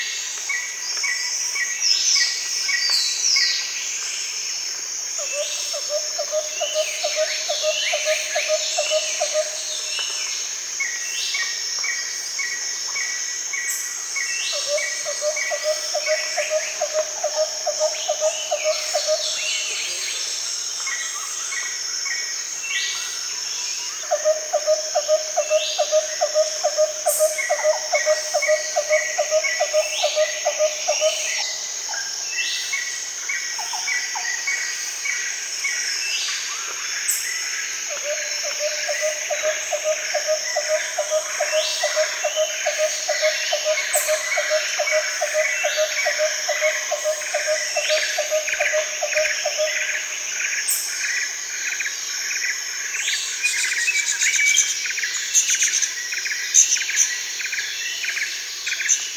jungle.ogg